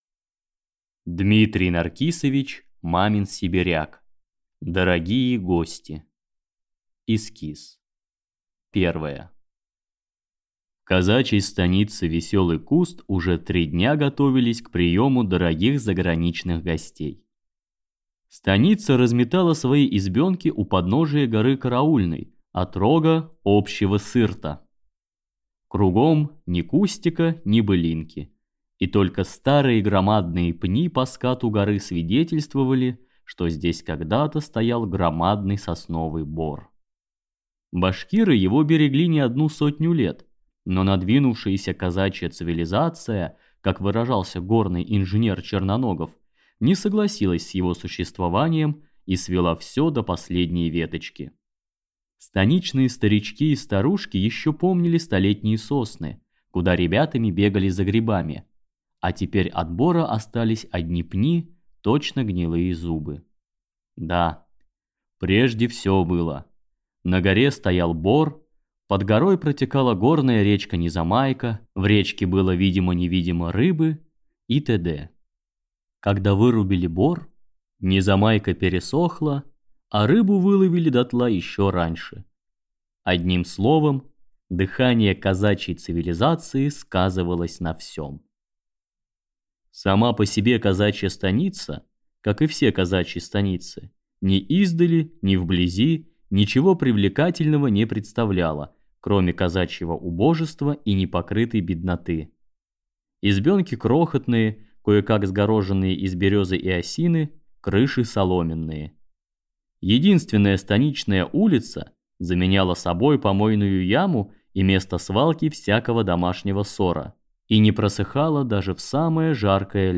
Аудиокнига Дорогие гости | Библиотека аудиокниг